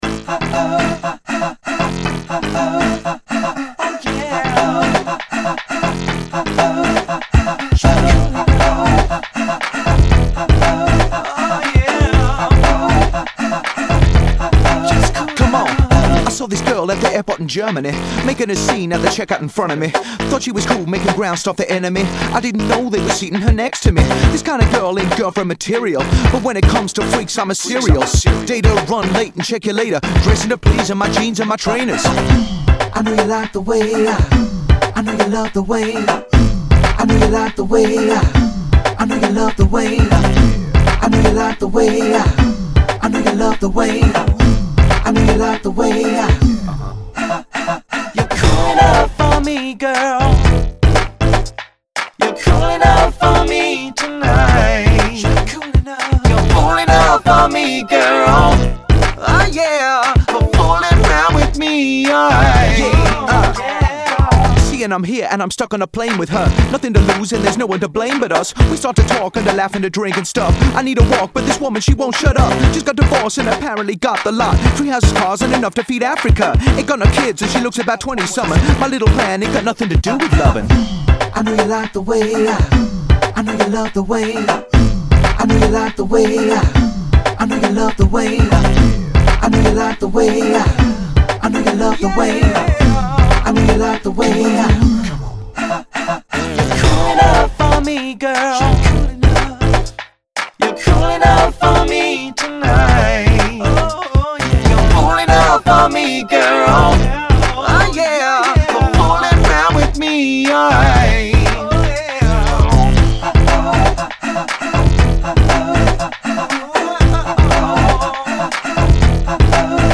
Uptempo Mlvx, Full band